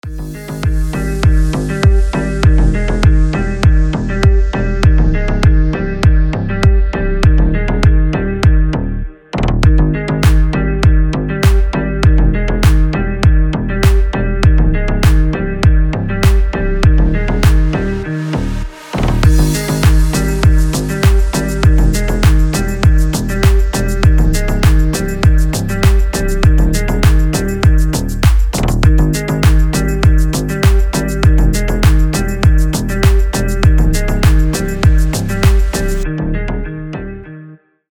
• Качество: 320, Stereo
deep house
без слов
Мрачновая, но красивая мелодия